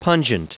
Prononciation du mot pungent en anglais (fichier audio)
Prononciation du mot : pungent